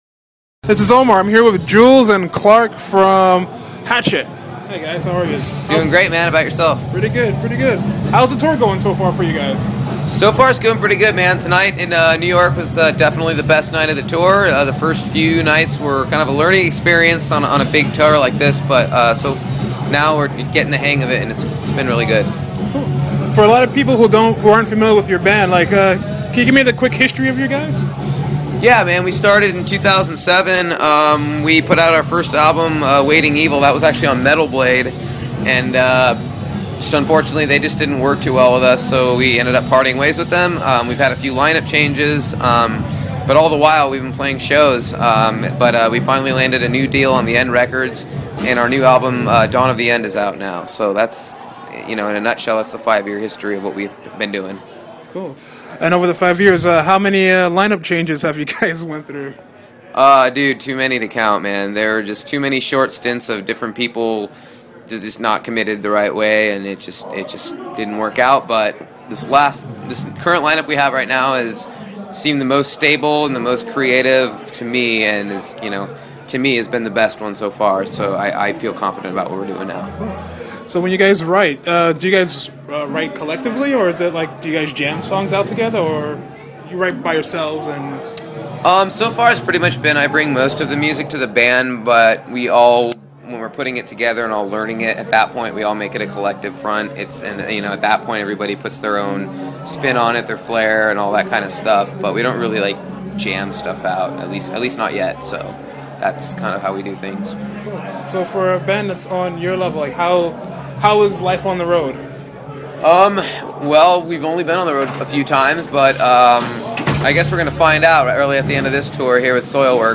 Interviewed By: